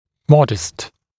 [‘mɔdɪst][‘модист]скромный, небольшой, умеренный